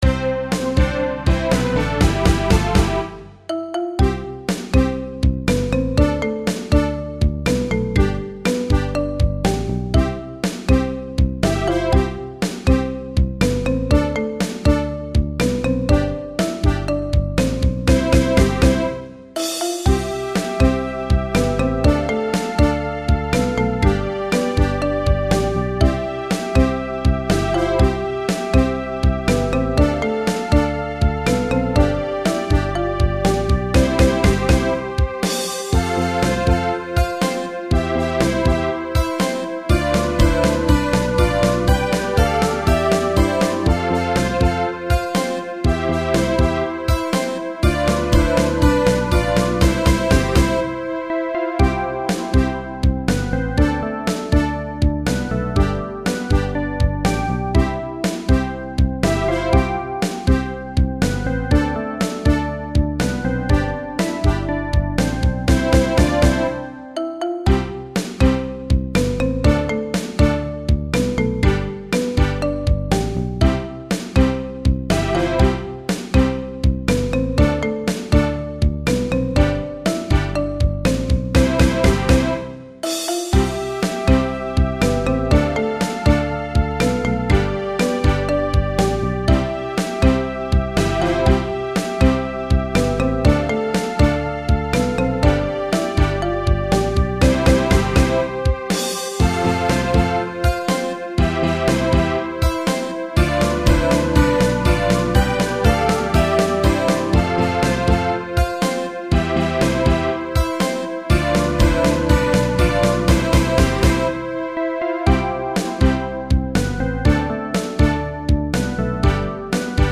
なので、今回はベースでリズムを作ってみた。
マリンバとかをメロディ、ブラスをオブリガートとして作ってみた。パート毎に楽器を変えて、パートの区切りを付けてみた。